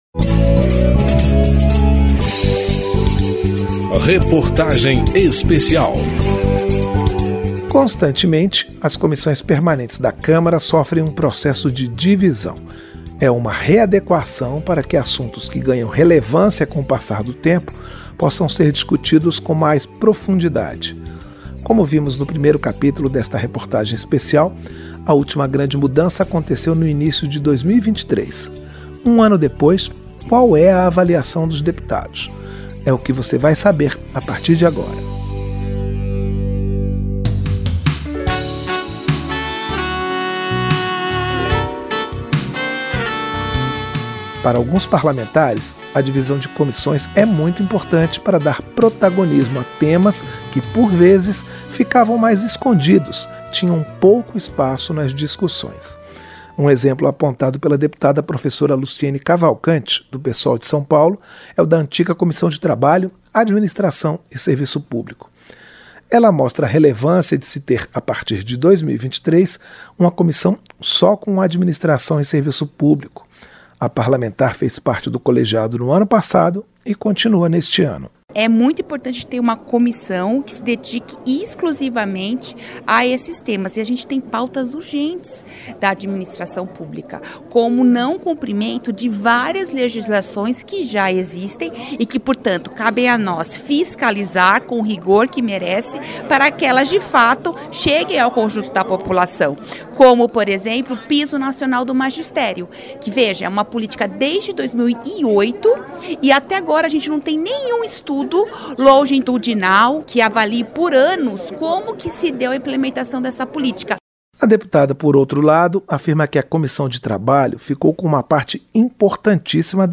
Reportagem Especial
Entrevistas nesse capítulo: as deputadas professora Luciene Cavalcante (Psol-SP) e Rogéria Santos (Republicanos-BA); e os deputados Átila Lins (PSD-AM), Henderson Pinto (MDB-PA), Augusto Coutinho (Republicanos-PE) e Vitor Lippi (PSDB-SP).